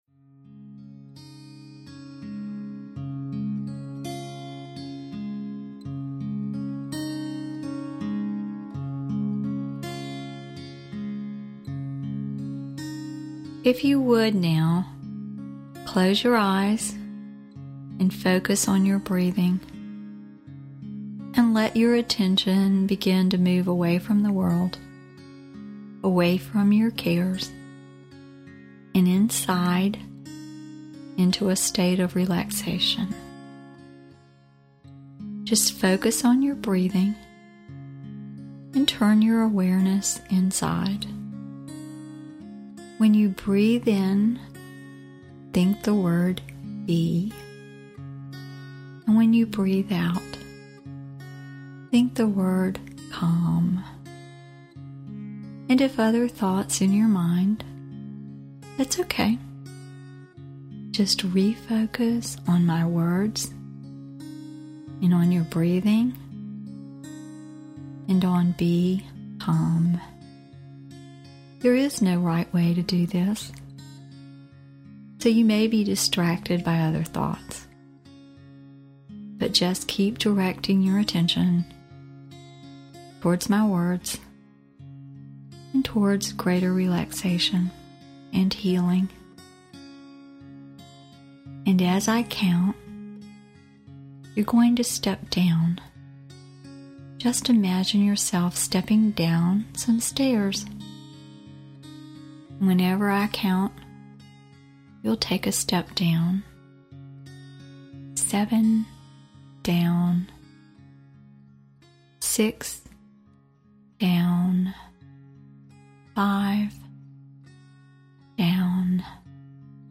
The "Train Your Brain" Series of Meditative Soundtracks